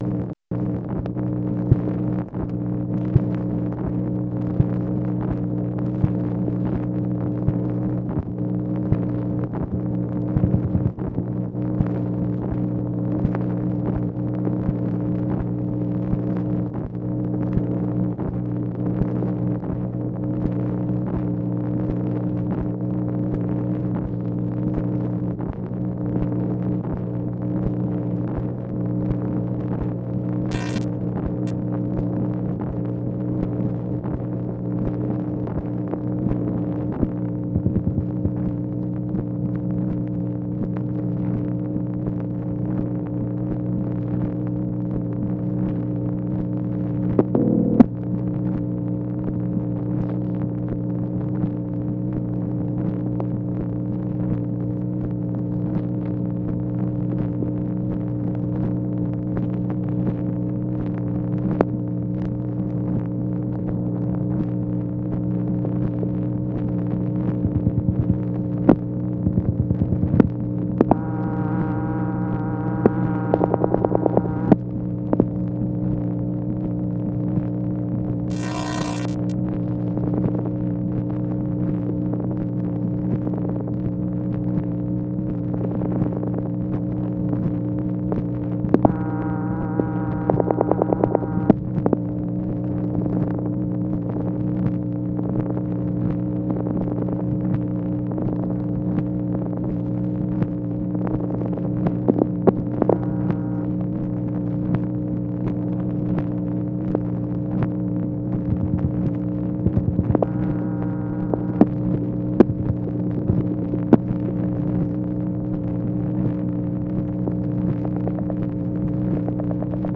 MACHINE NOISE, October 23, 1964
Secret White House Tapes | Lyndon B. Johnson Presidency